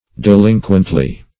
delinquently - definition of delinquently - synonyms, pronunciation, spelling from Free Dictionary Search Result for " delinquently" : The Collaborative International Dictionary of English v.0.48: Delinquently \De*lin"quent*ly\, adv. So as to fail in duty.